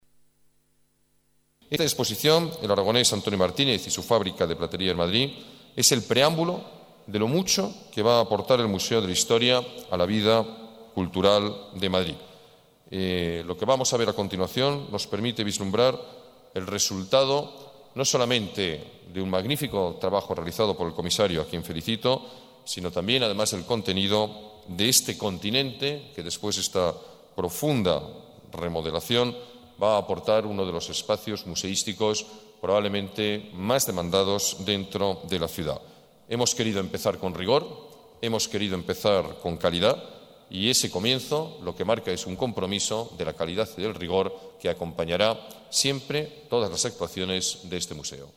Nueva ventana:Declaraciones del alcalde, Alberto Ruiz-Gallardón: Museo de la Historia